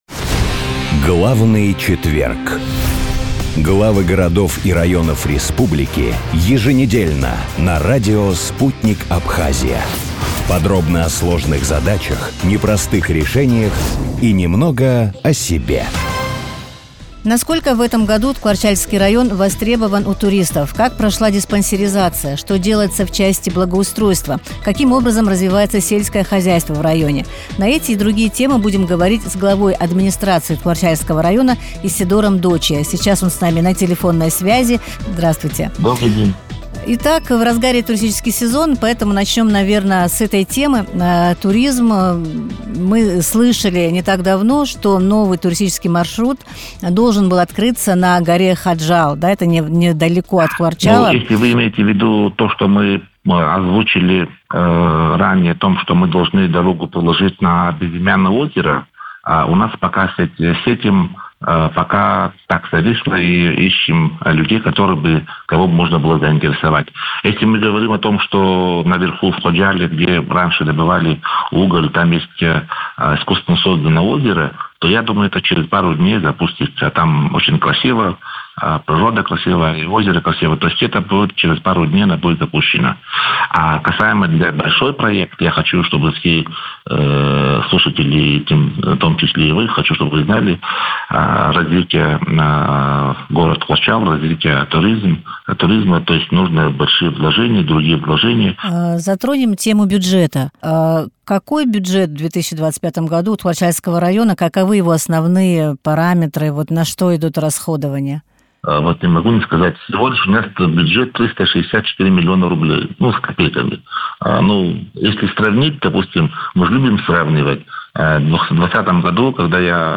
Бюджет, дороги, коммуникации: интервью с главой Ткуарчалского района
Как проходит лето в Ткуарчалском районе, как исполняется бюджет, что происходит в сельском хозяйстве и сфере здравоохранения? Глава администрации района Исидор Дочия рассказал обо всем в интервью радио Sputnik.